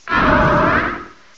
The cries from Chespin to Calyrex are now inserted as compressed cries